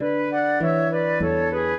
flute-harp
minuet6-8.wav